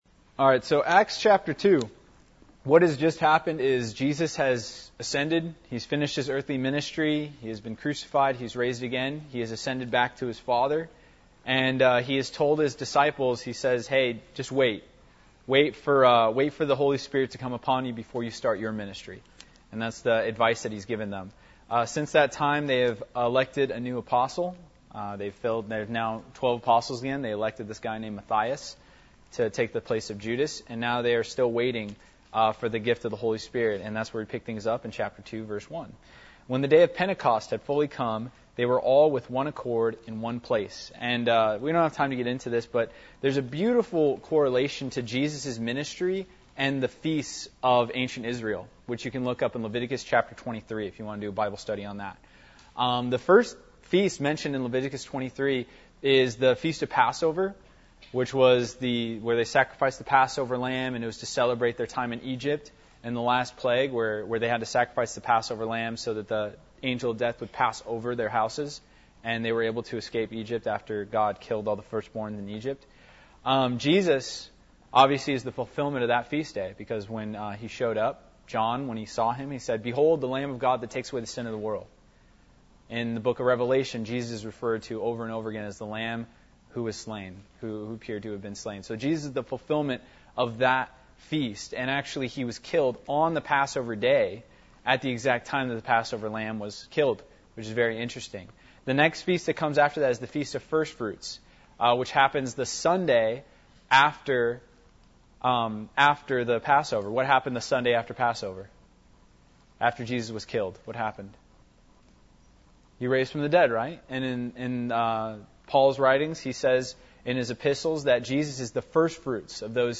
Service Type: Student Ministries